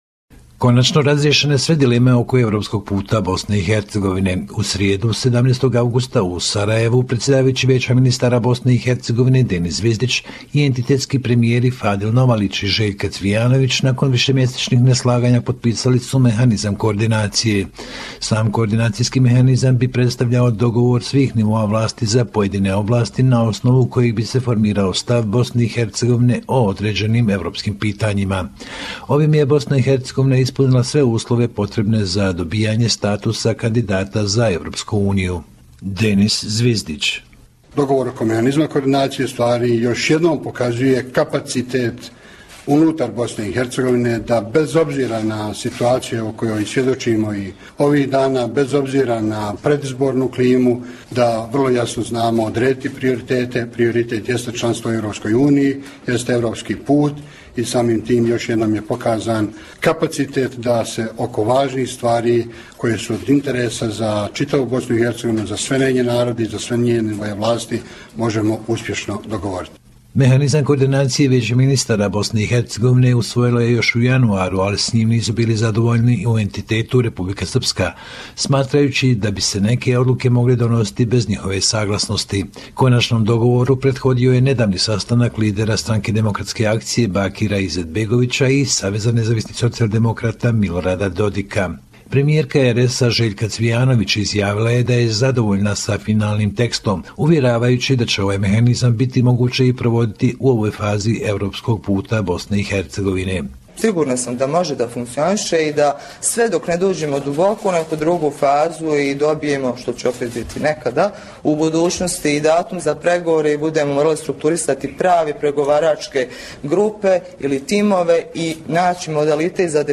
Report: The Chairman of the Council of Ministers of Bosnia and Herzegovina and entity governments approved the text of the coordination mechanism